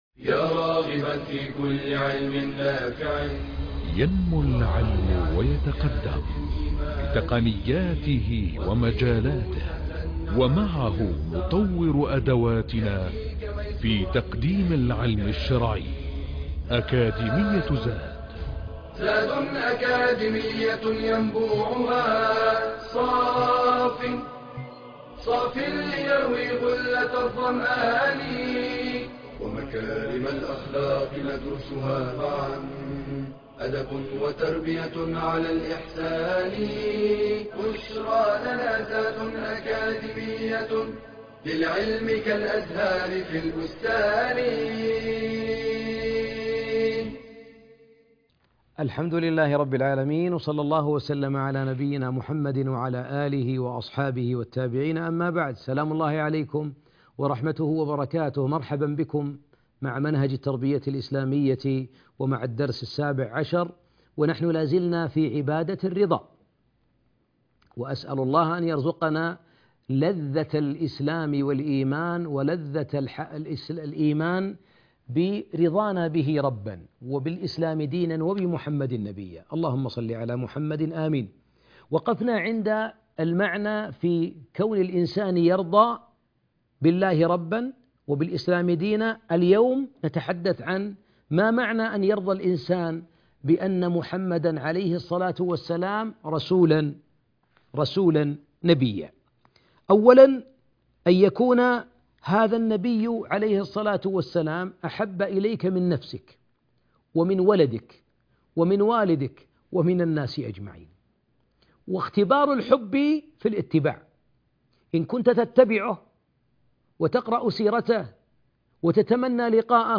المحاضرة السابعة عشرة - الرضا بسيدنا محمد